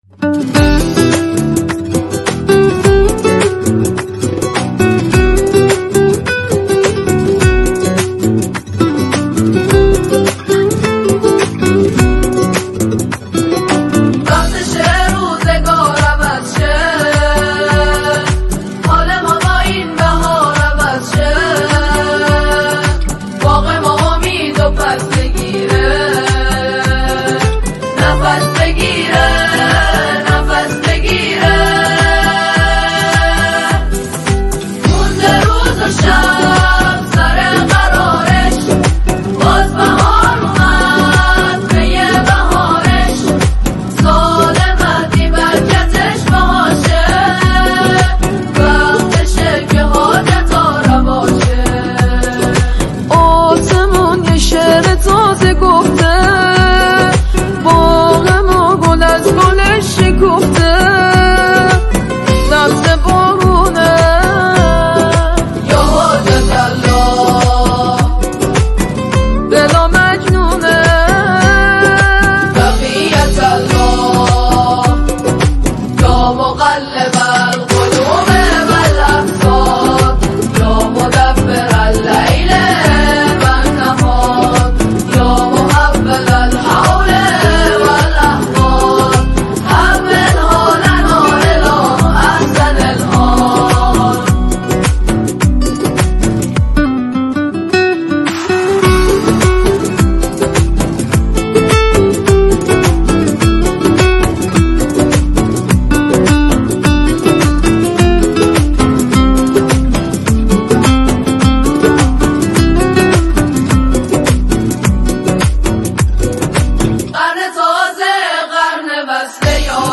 نماهنگ زیبای جدید